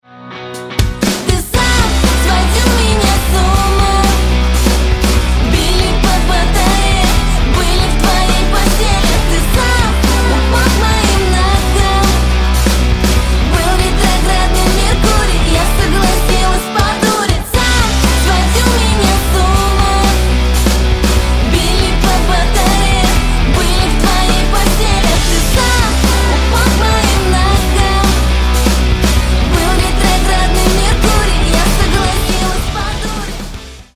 Рок Металл Рингтоны